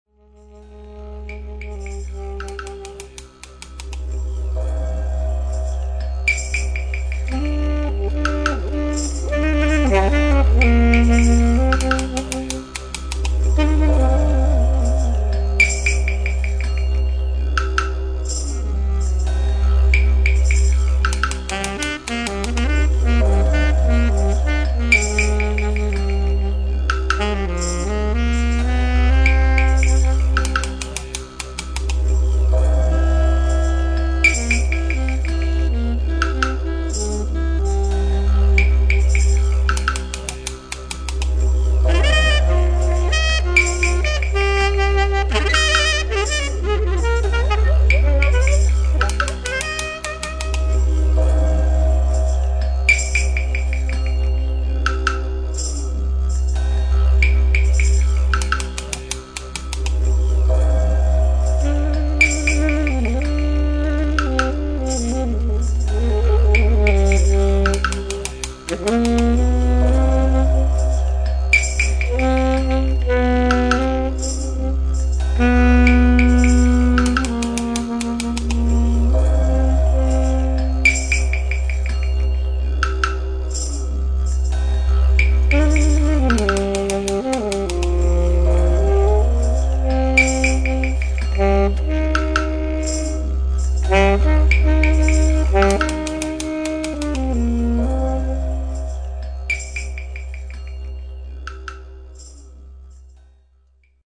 atmosph�rischem Ethnojazzpunk
Didgeridoo, Altsaxophon, Percussion und Xylophon